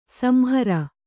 ♪ samhara